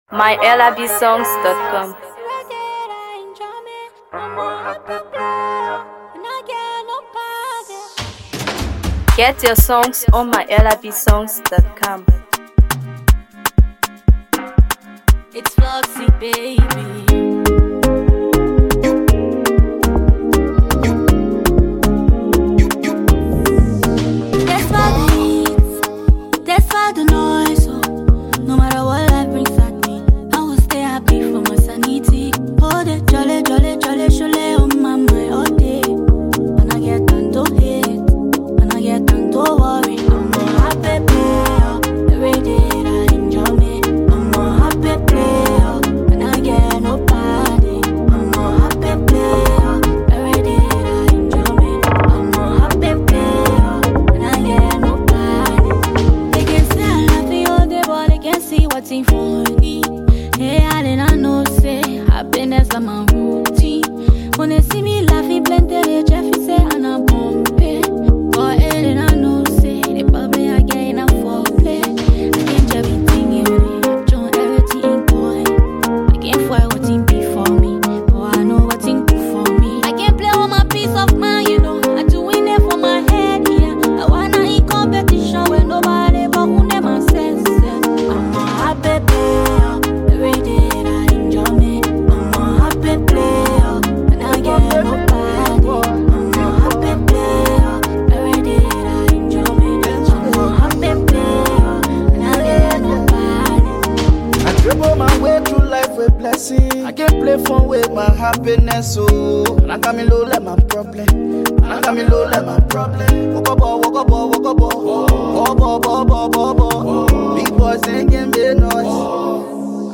Afro PopMusic
creating a track that’s both catchy and impactful.